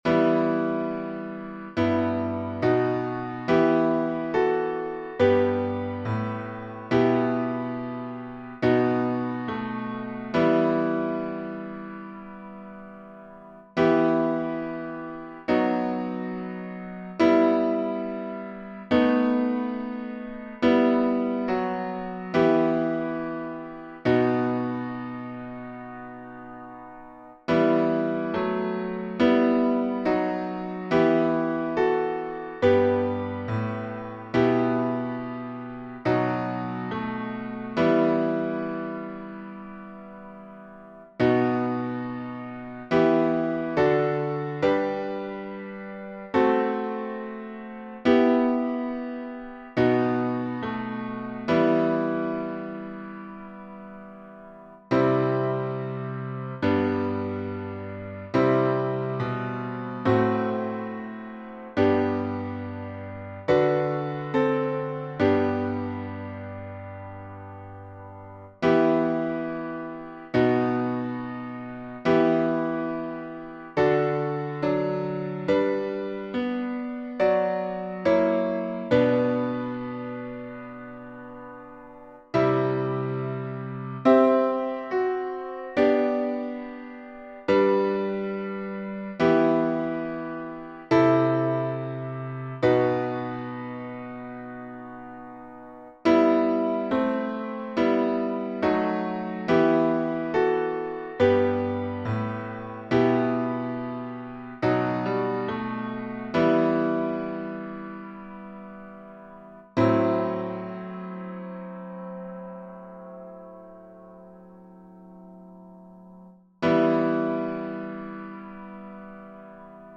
Words by Charles Wesley (1707-1788), 1740Tune: ABERYSTWYTH by Joseph Parry (1841-1903)Key signature: E minor (1 sharp)Time signature: 4/2Meter: 7.7.7.7.D.Public Domain1.